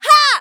YX发力3.wav 0:00.00 0:00.42 YX发力3.wav WAV · 36 KB · 單聲道 (1ch) 下载文件 本站所有音效均采用 CC0 授权 ，可免费用于商业与个人项目，无需署名。
人声采集素材